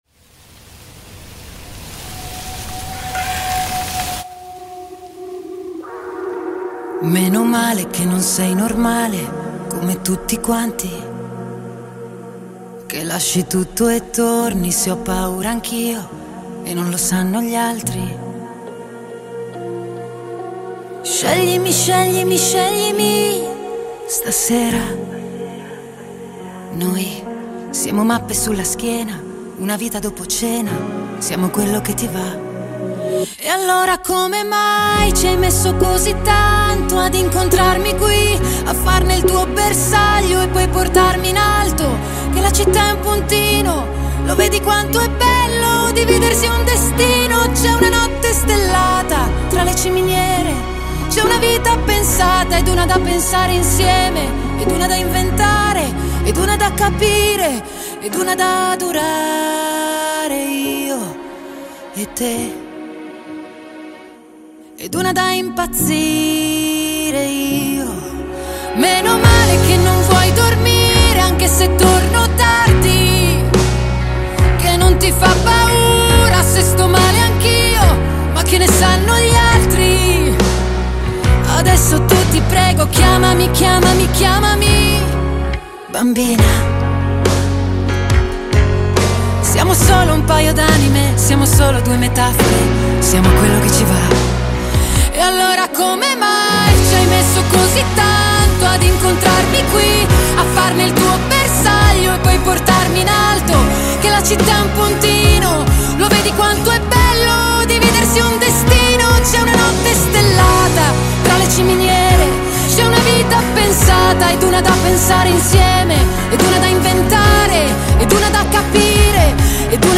آهنگ ایتالیایی با صدای زن عاشقانه